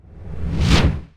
spell-whoosh-3.ogg